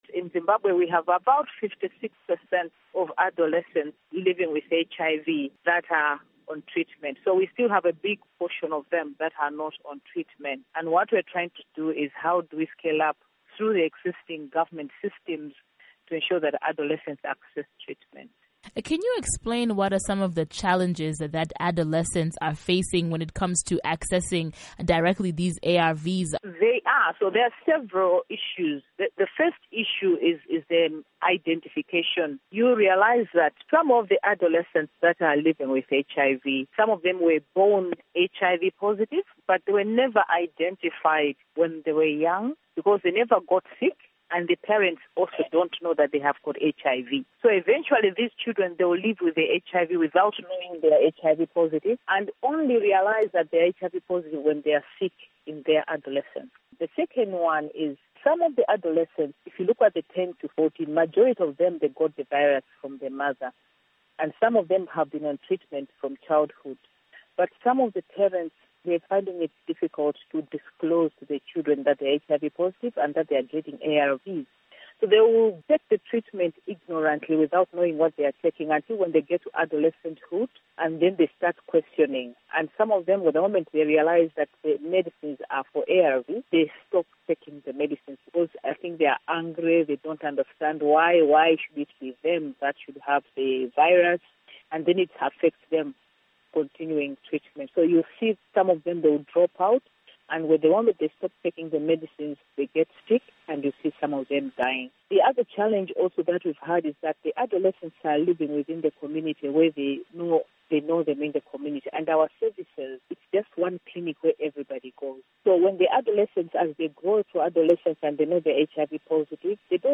Health Feature Interview